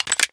Index of /server/sound/weapons/dod_m1911
colt_clipin.wav